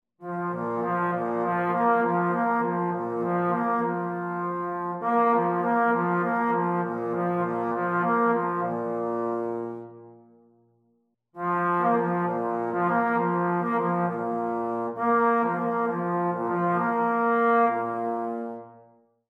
A short extract: Trombone Slurs